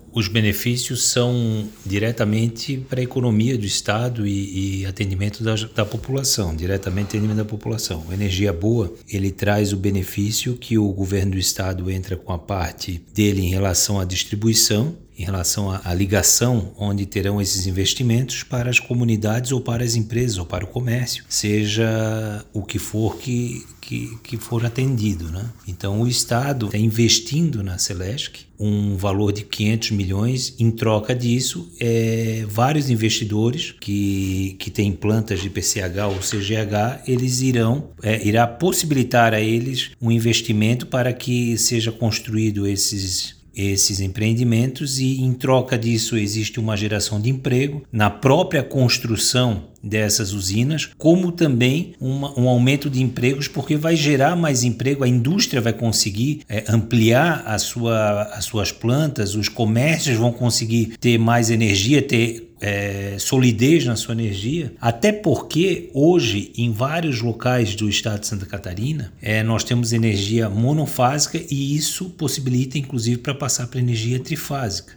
O secretário adjunto da Sicos, Jonianderson Menezes, destaca que o Programa vai gerar mais emprego porque as indústrias vão conseguir ampliar as suas plantas e os comércios vão ampliar suas operações: